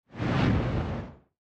fissure-explosion-2.ogg